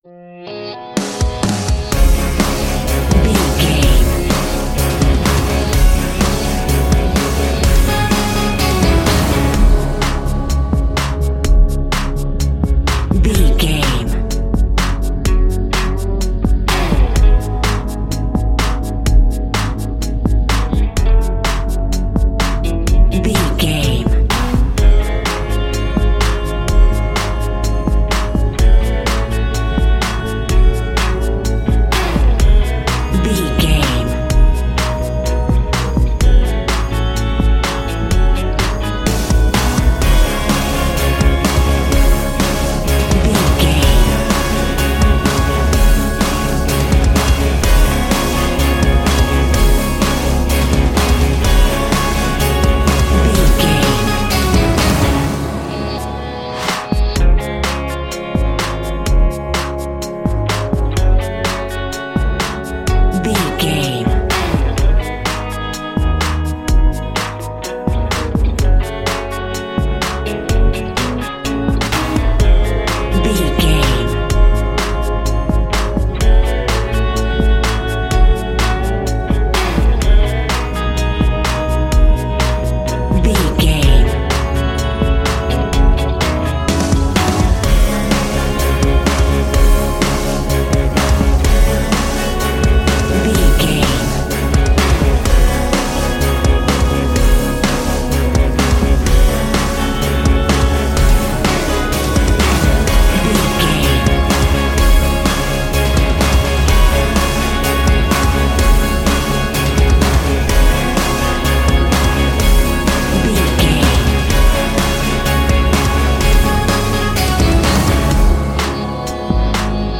Epic / Action
In-crescendo
Phrygian
C#
drums
electric guitar
bass guitar
hard rock
lead guitar
aggressive
energetic
intense
nu metal
alternative metal